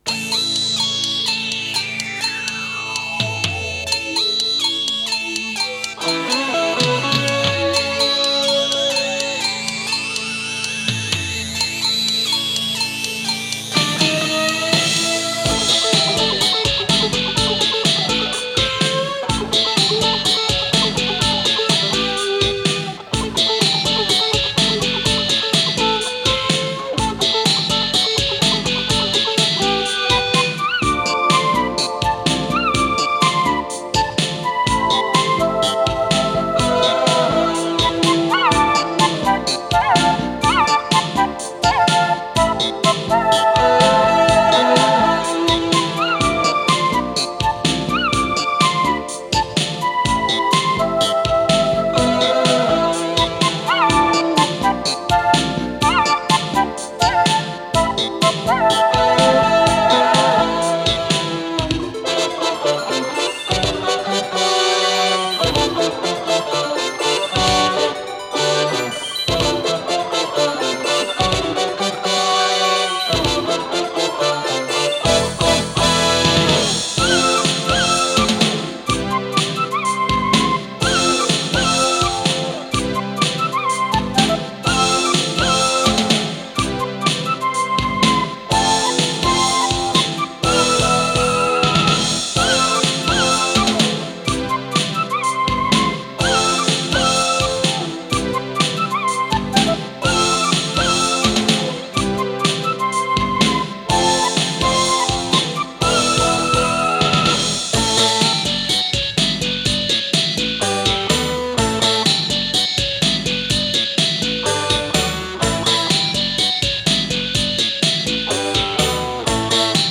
с профессиональной магнитной ленты
клавишные
гитара
ВариантДубль моно